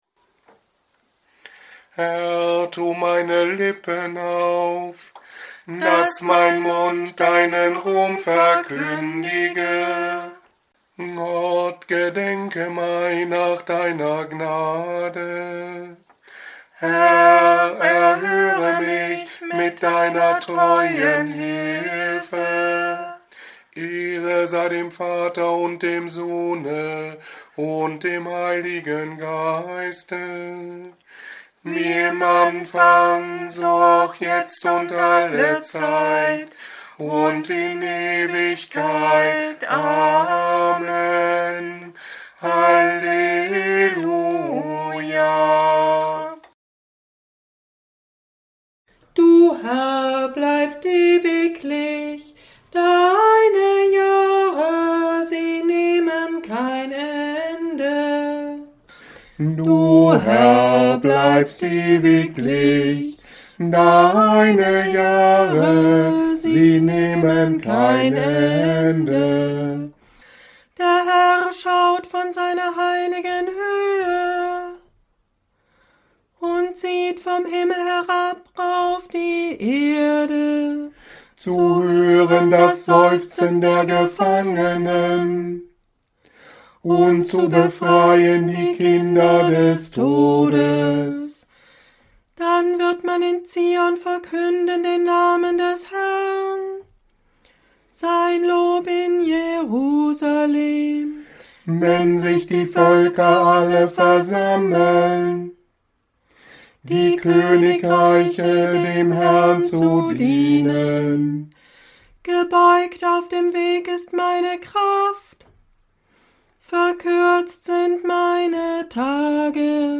Stundengebete in der Woche nach dem Drittletzten Sonntag im Kirchenjahr 2025